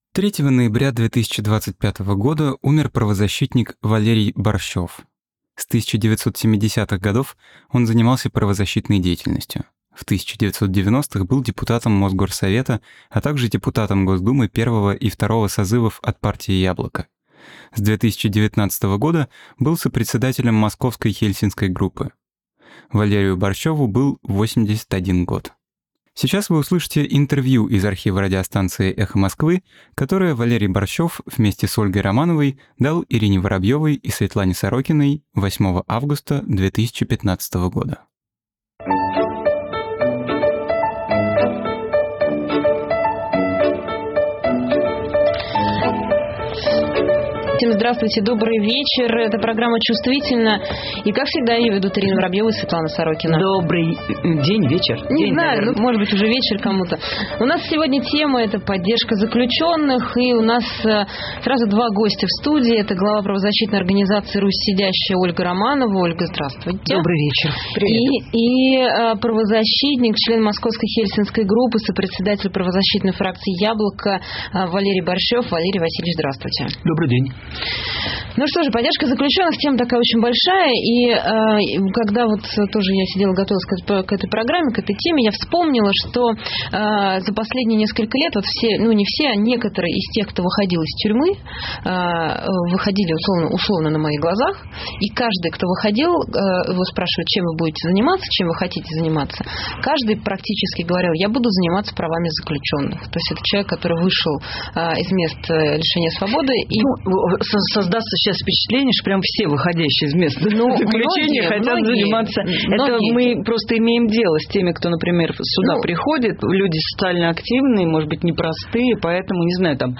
Интервью из архива «Эха Москвы» от 08.08.15